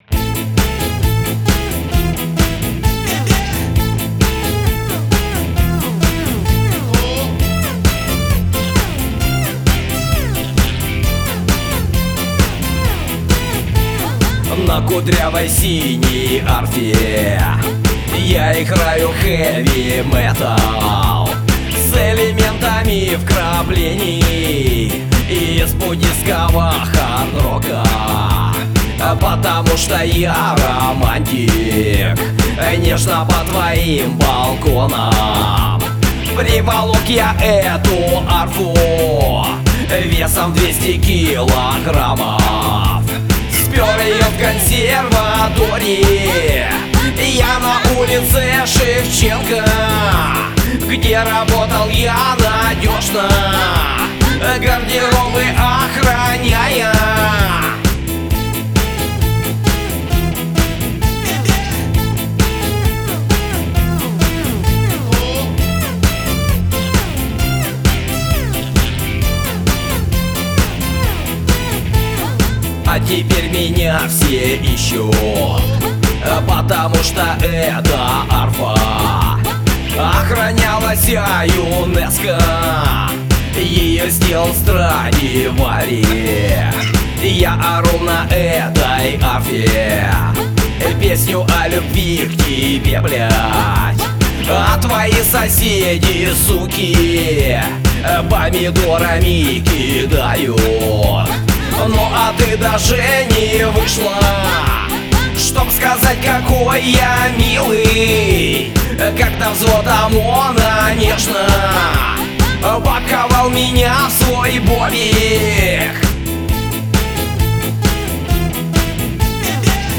• Жанр: Альтернатива, Русская музыка